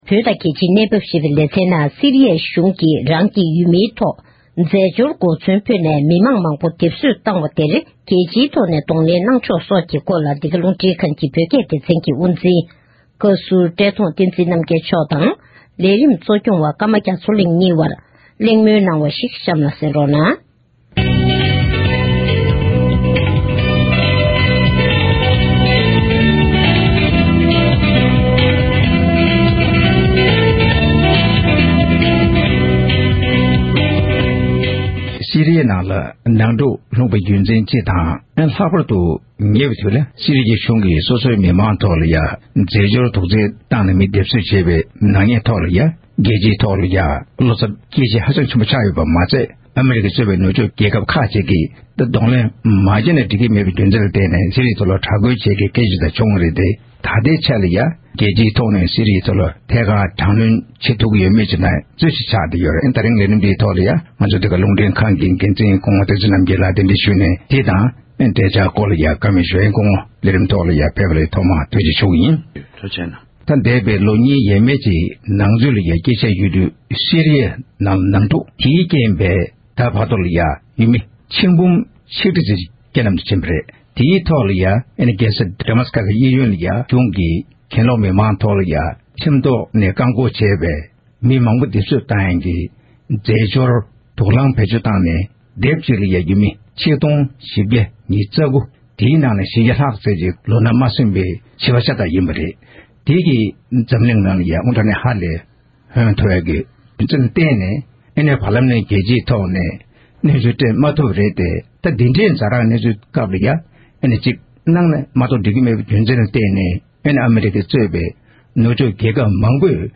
གླེང་མོལ་ཞུས་པ་ཞིག་གསན་རོགས༎